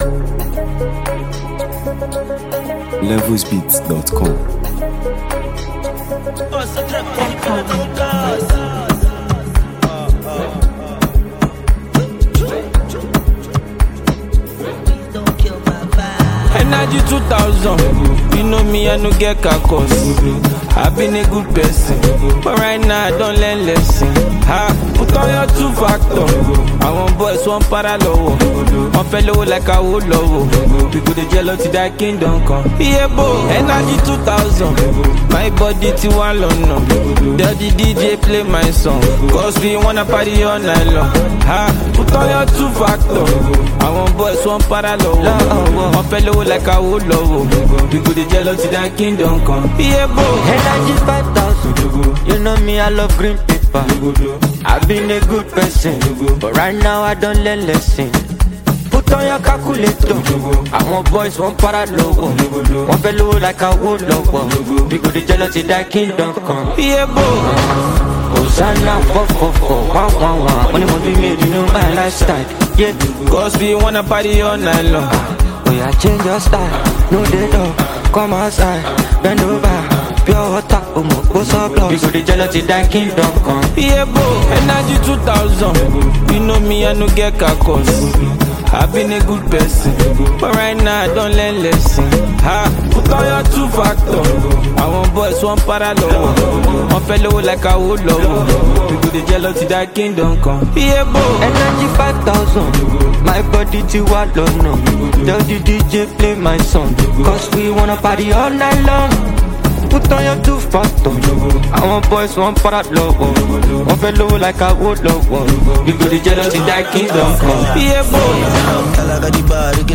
smooth delivery
signature vocal flair
amapiano-powered energy